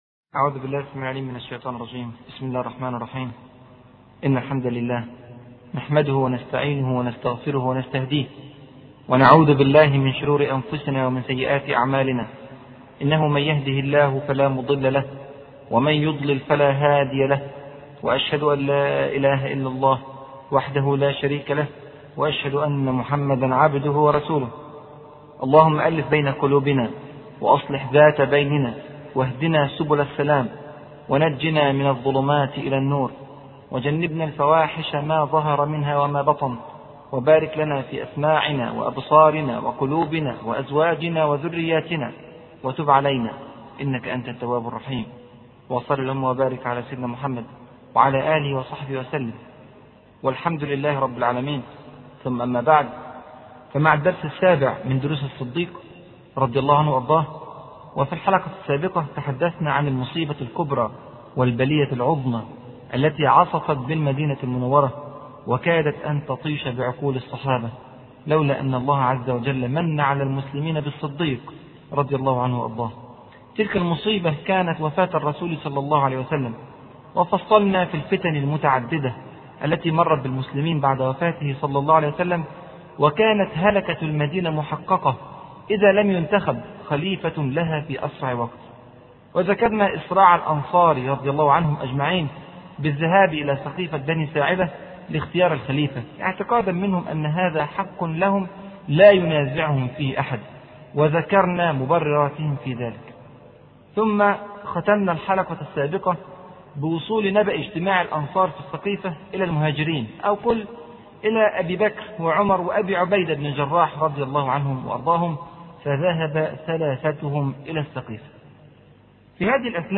أرشيف الإسلام - ~ أرشيف صوتي لدروس وخطب ومحاضرات د. راغب السرجاني